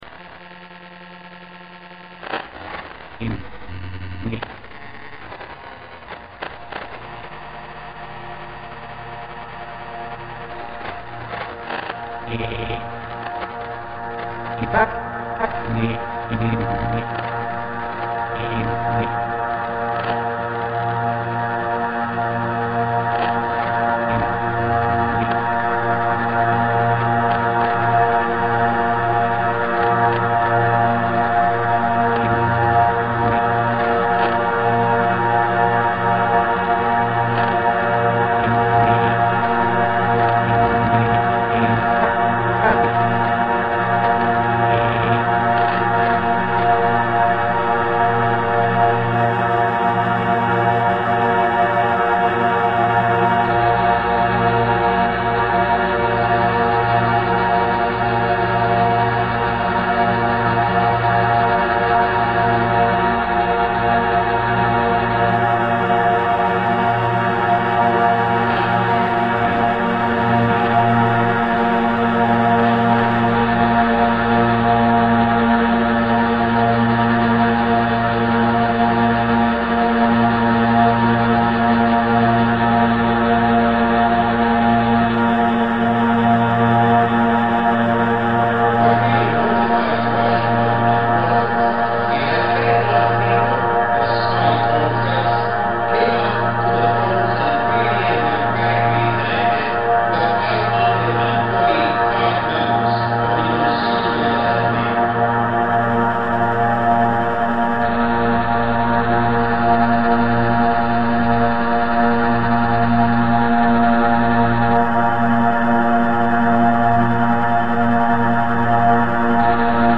Shortwave voices are heard as revenants of the radio frequencies, returning and returning again to haunt places they have never been, connecting moment to moment in an already-present remembrance, an already-remembered present. The source material is a transmission on Radio Havana Cuba, 2 August 2017
documenting and reimagining the sounds of shortwave radio